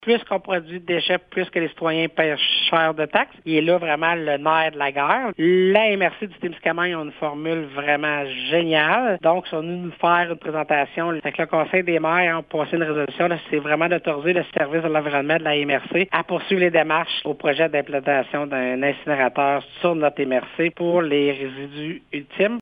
Écoutons à ce sujet la préfète de la Vallée-de-la-Gatineau, Chantal Lamarche :